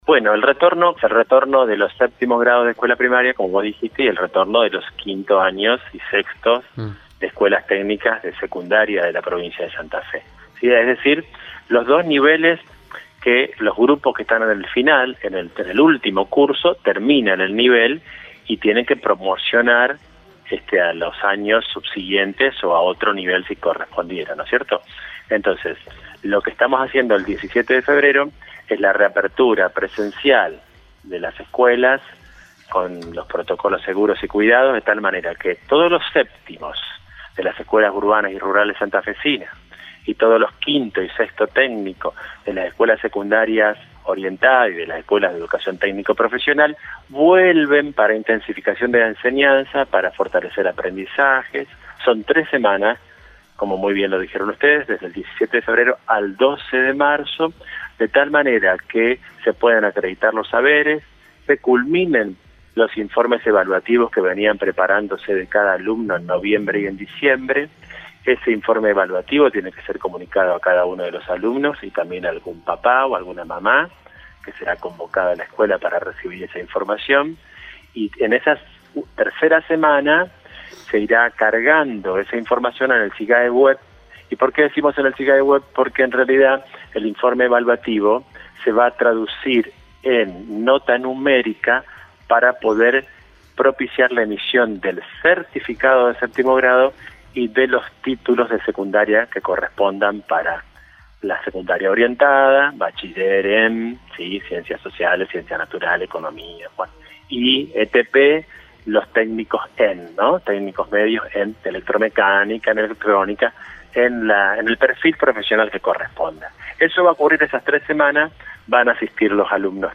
El secretario de Educación de la provincia de Santa Fe, Víctor Debloc, detalló como será la vuelta a clases en Radio EME.